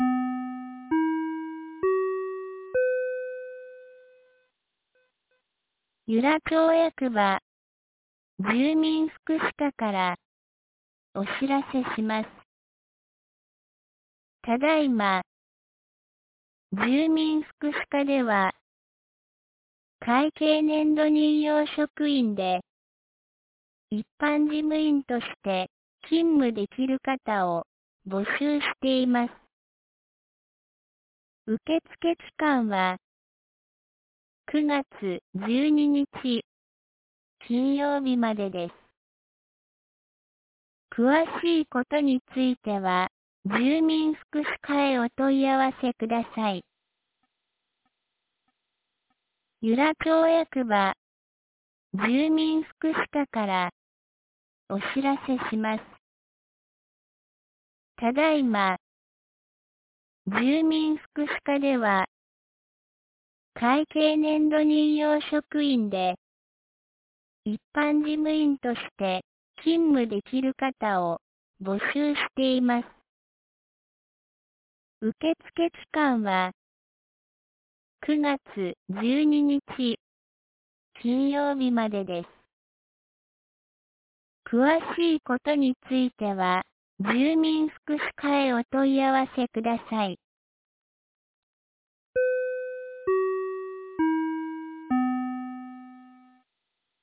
2025年09月02日 17時12分に、由良町から全地区へ放送がありました。